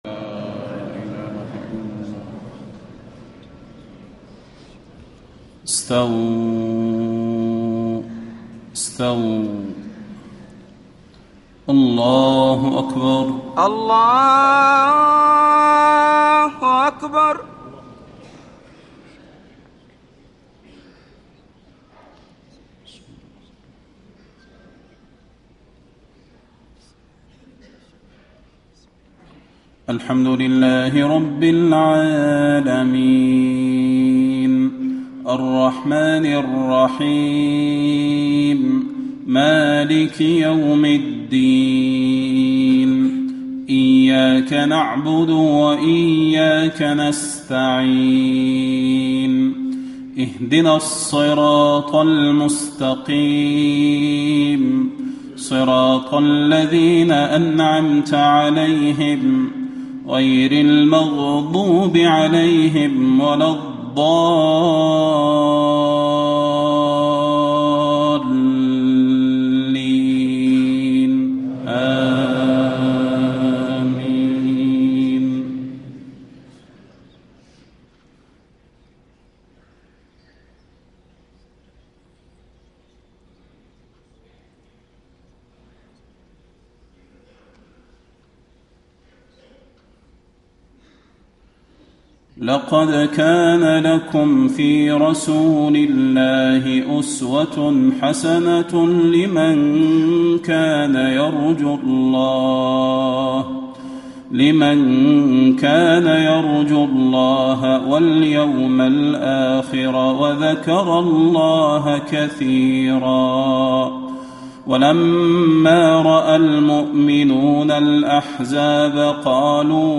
صلاة المغرب 1 - 3 - 1434هـ من سورة الأحزاب > 1434 🕌 > الفروض - تلاوات الحرمين